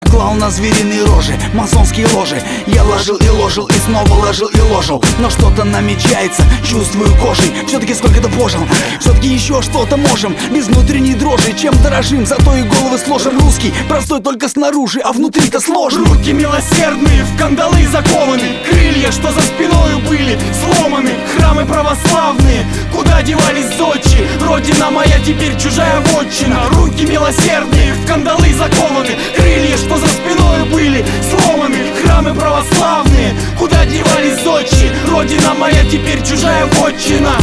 • Качество: 320, Stereo
русский рэп
пацанские